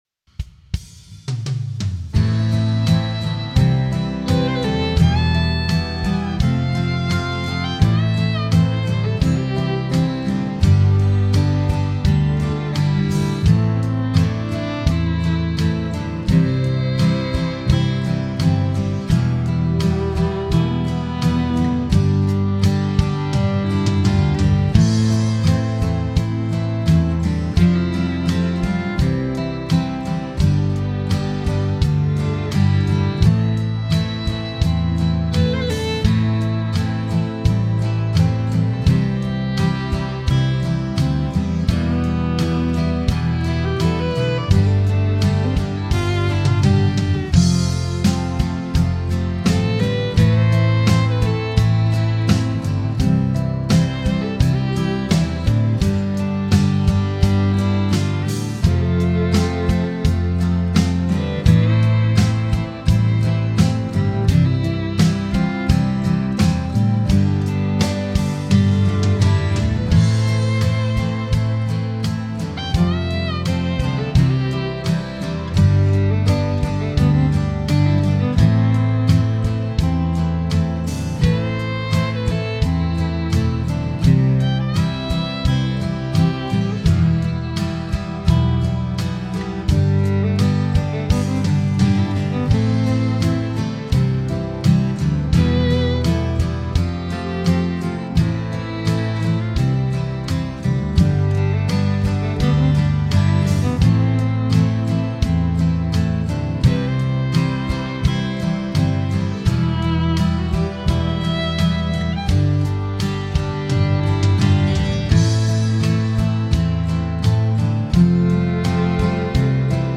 Home > Music > Blues > Bright > Smooth > Folk